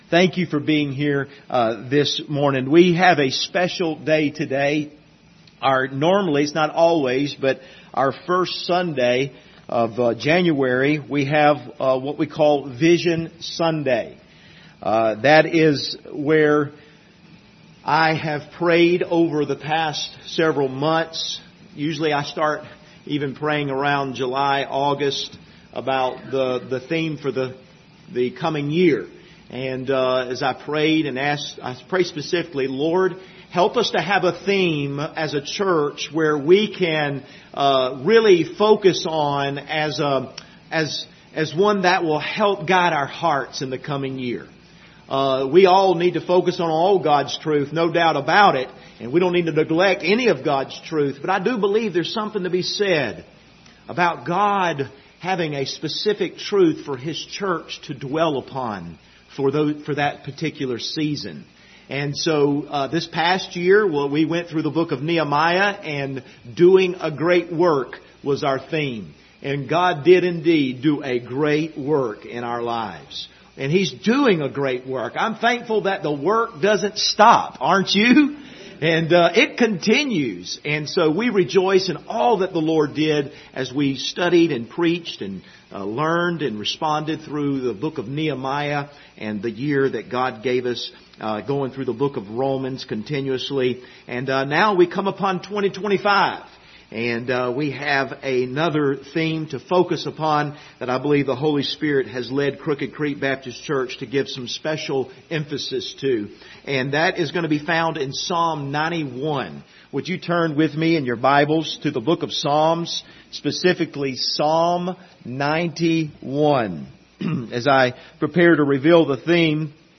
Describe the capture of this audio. Passage: Psalm 91 Service Type: Sunday Morning View the video on Facebook Topics